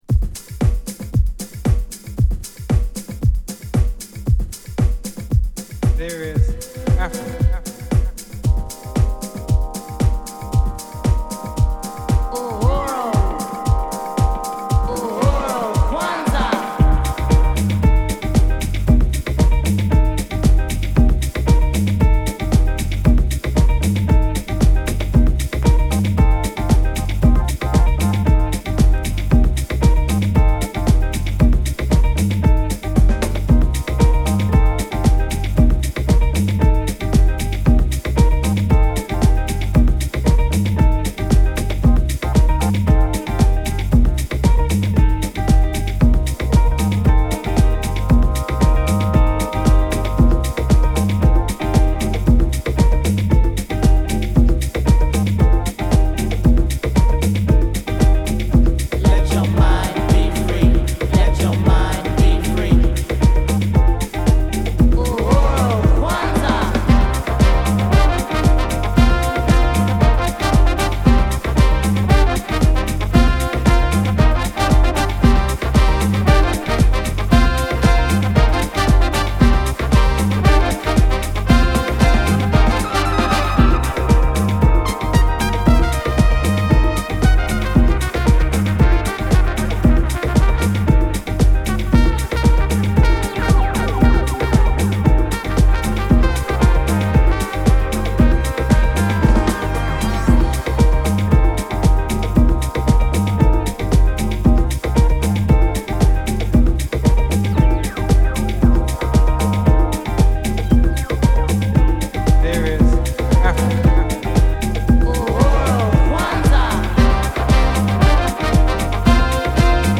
アフロ・ビート・ジャズダンサー
ワルツタイムのパーカッシヴでスピリチュアルな
ライブ感のあるジャズ・ヴォーカル・ハウス